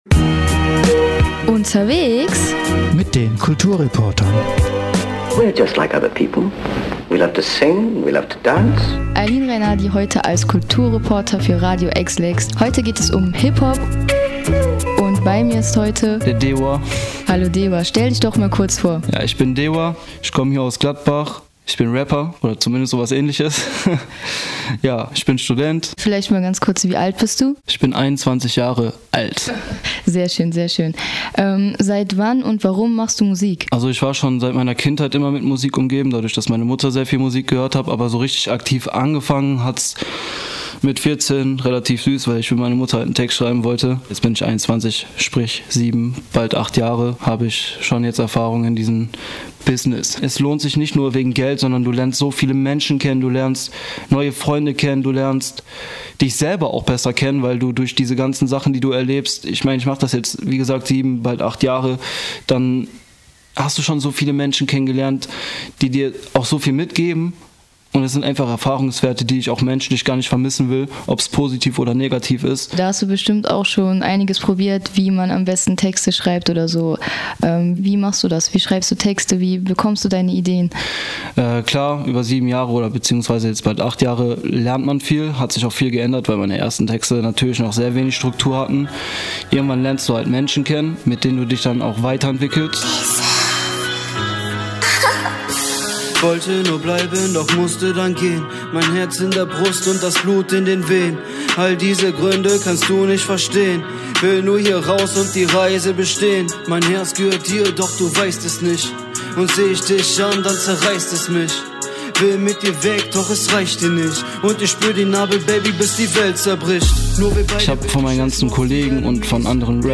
DeutschRap aus MG